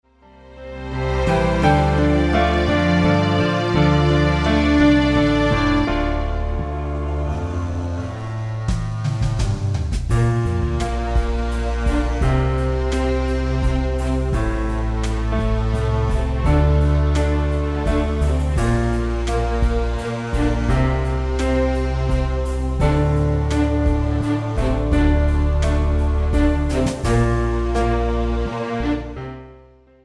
delikatny niczym sen, klimat odprężenia